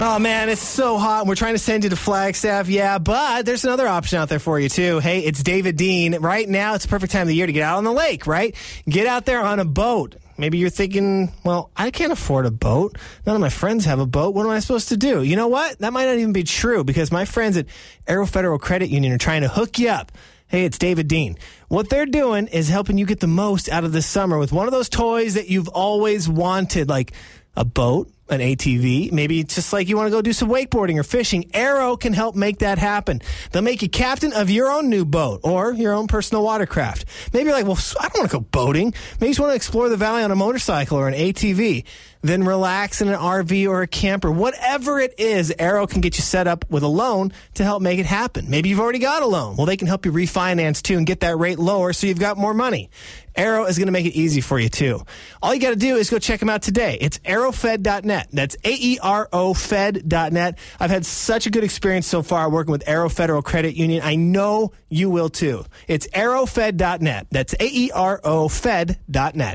BA researched and interviewed numerous top stations and talents in the Phoenix market before deciding upon the top New Country station and their Morning Show talent.